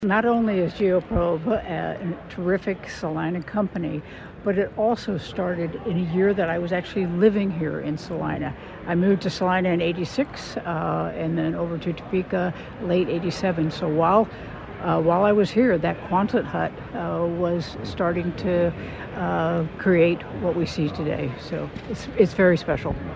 She told KSAL News this one was a little extra special, with her connection to Salina.